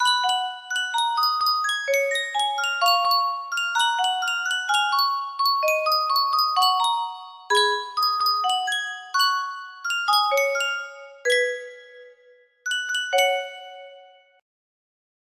Yunsheng Music Box - Unknown Tune 1729 music box melody
Full range 60